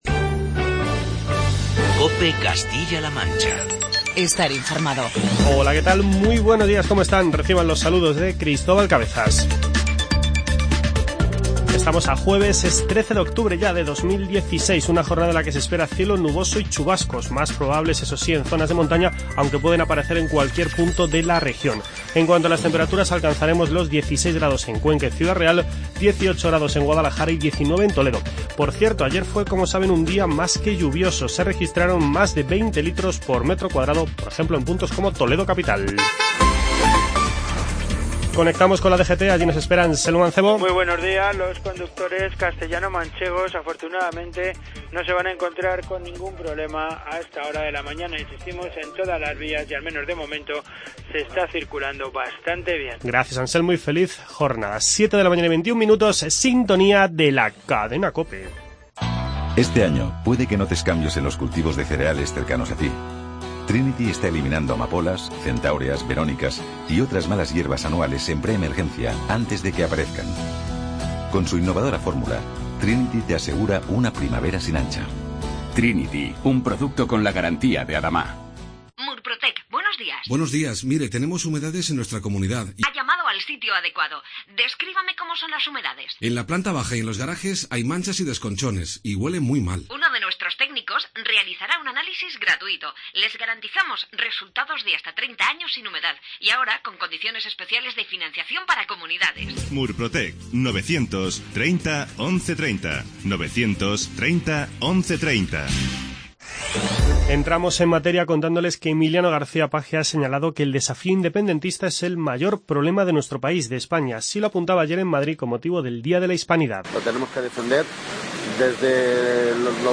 Informativo COPE Castilla-La Mancha